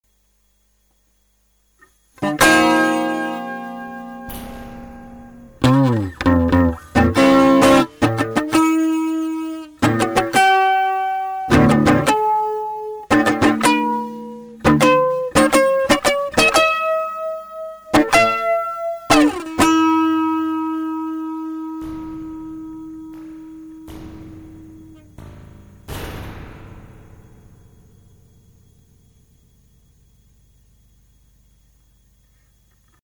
けちゃうかの、録音と再生の間へ ポンと丁挟み ノイズゼロ、